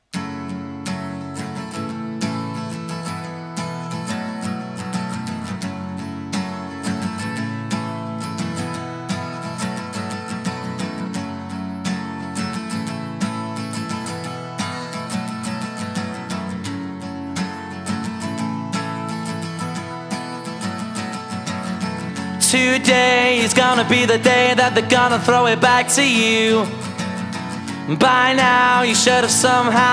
• Indie Rock